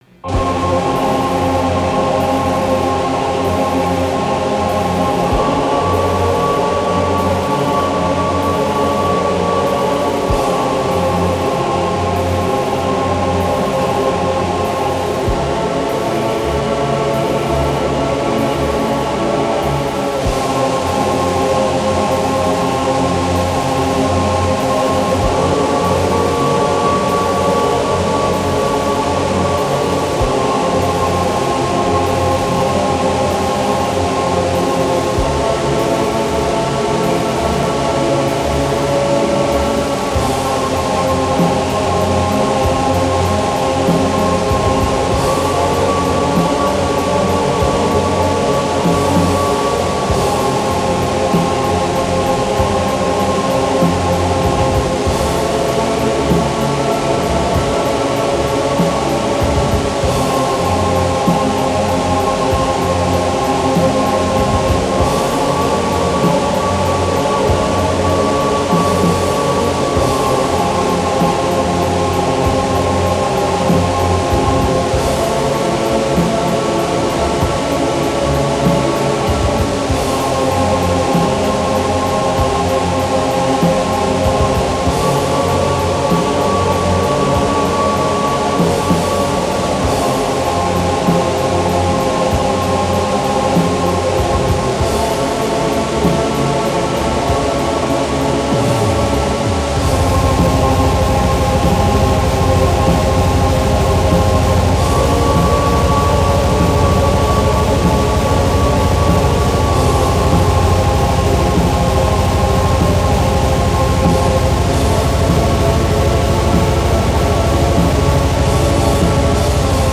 *фоновая композиция